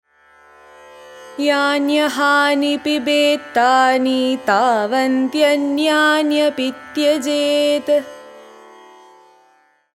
IMPORTANT SLOKA